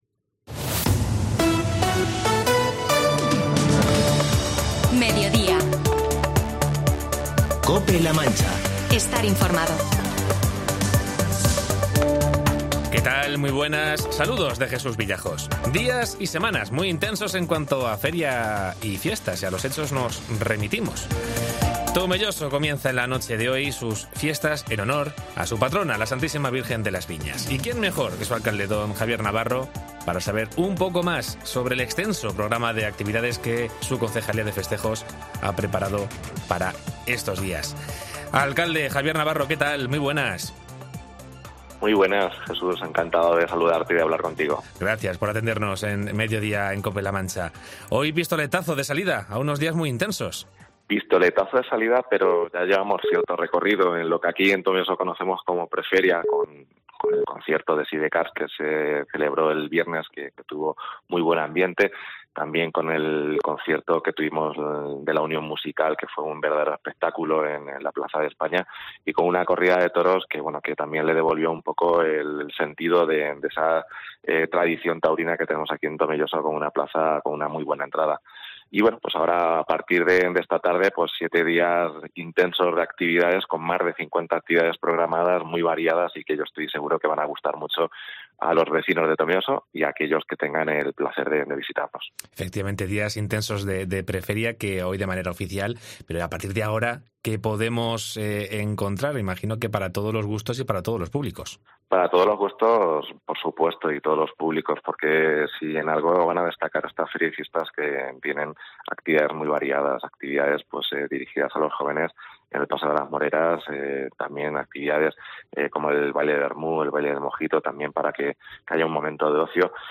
Entrevista al alcalde Javier Navarro con motivo de la Feria y Fiestas de Tomelloso